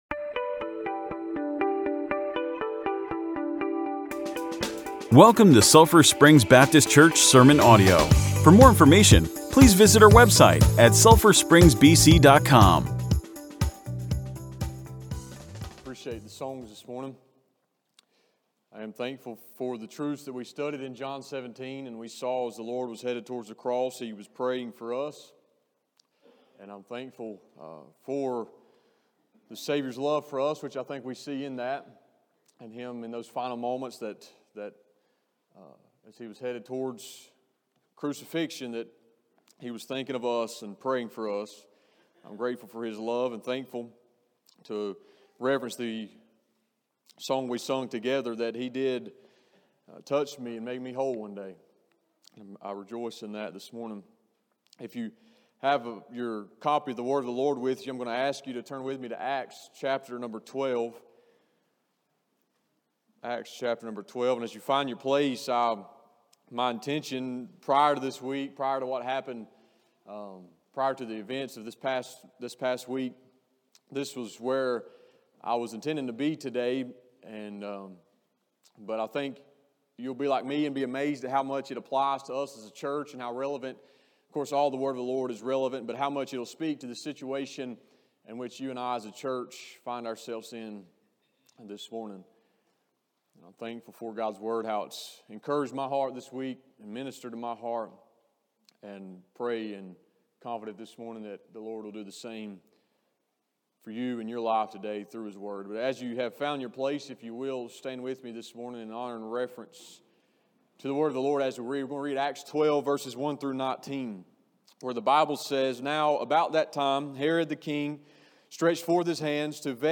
Sermons | Sulphur Springs Baptist Church